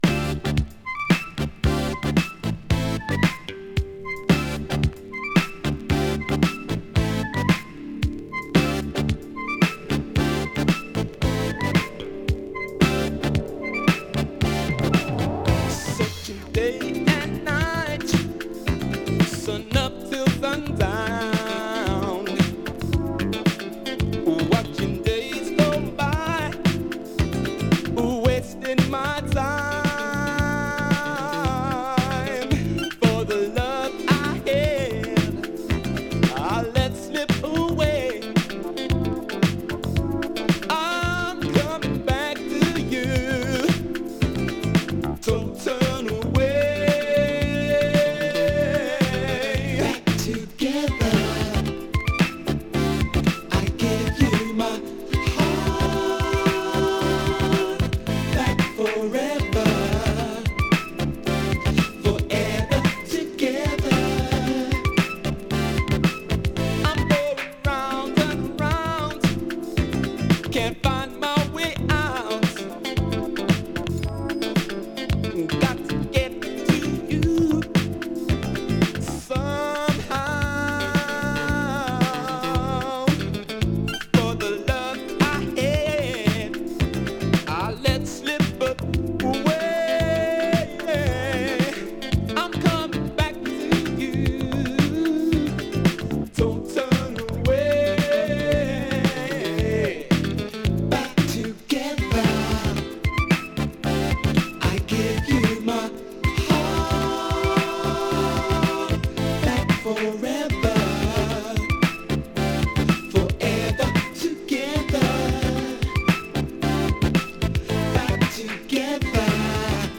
UK Modern Soul!
洗練されたコーラス・ワークが印象的なアーバンなUK産モダンソウル・ナンバーをカップリング！
【UK】【BOOGIE】【SOUL】